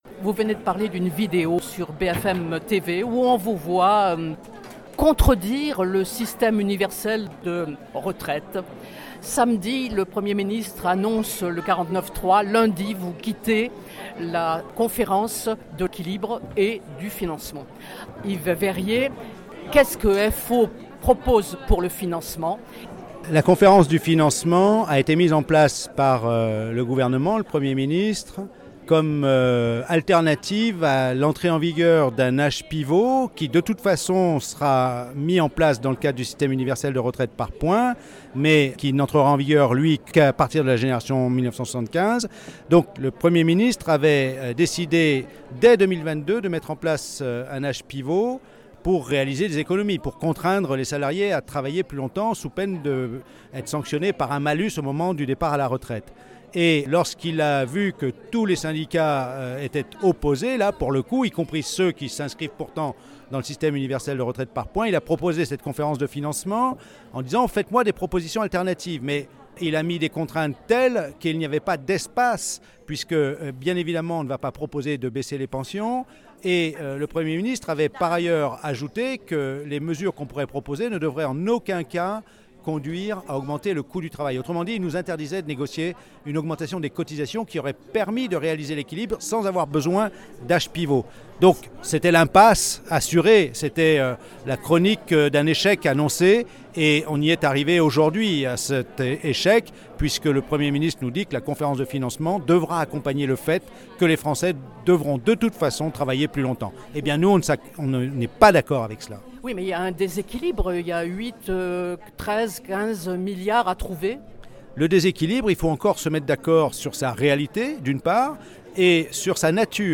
son_copie_petit-414.jpg Entretien avec Yves Veyrier, secrétaire général de Force Ouvrière (FO)